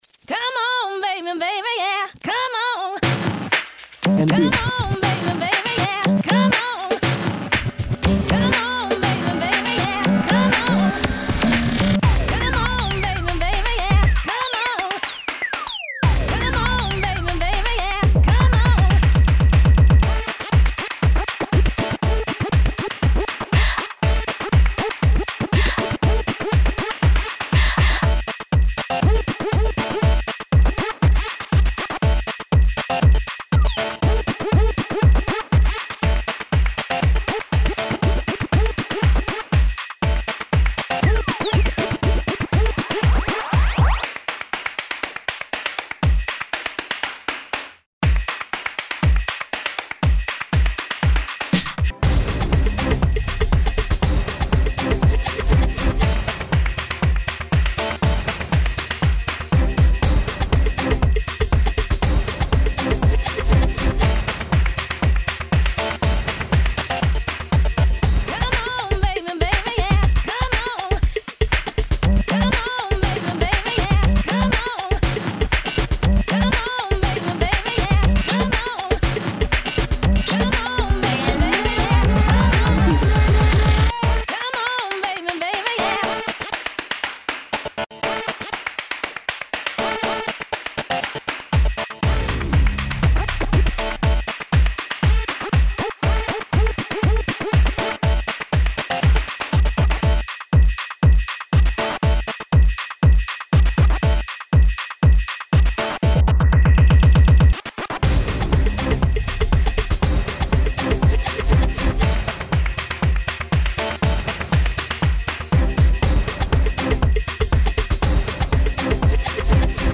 若い子の声って、ホントいいよね＾＾